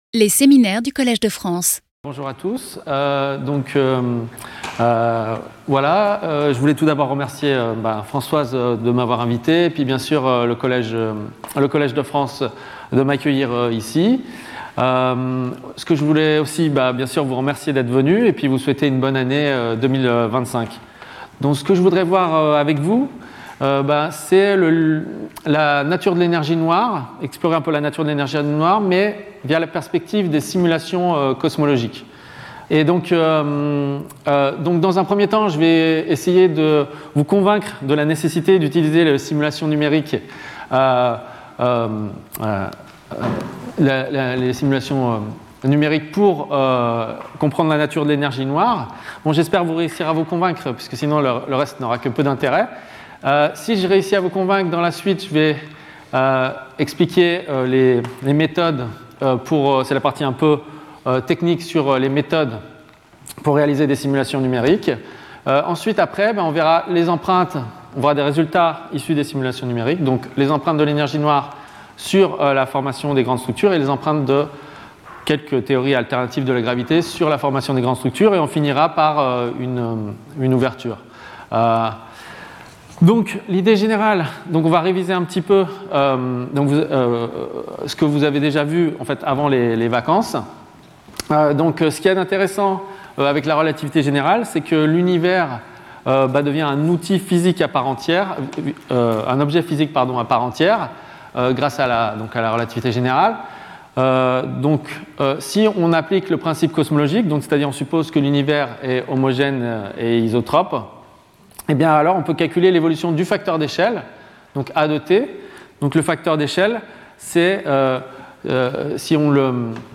In this seminar, I will present the fundamental contribution of numerical simulations to the problem of the nature of dark energy. Using models of the formation of the Universe's great structures, scientists are carrying out experiments "in-silico", varying the properties of dark energy and studying their consequences on the distribution of galaxies.